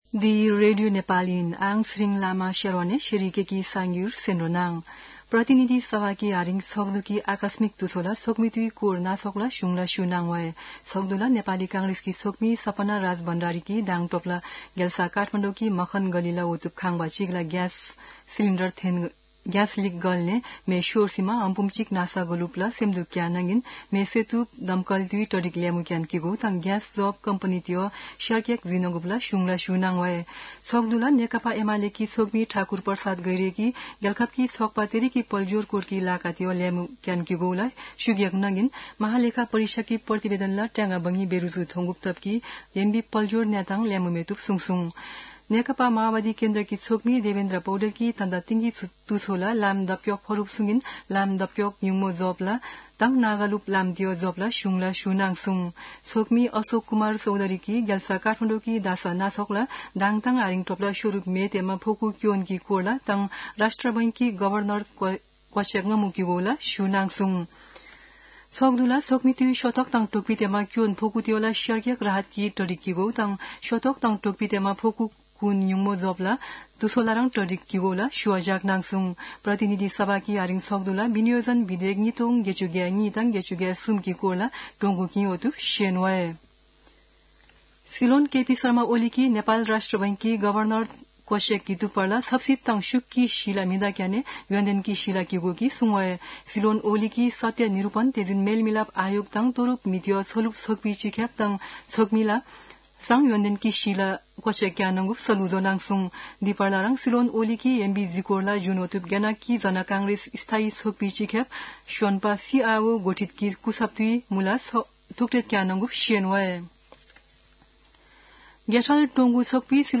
शेर्पा भाषाको समाचार : १ जेठ , २०८२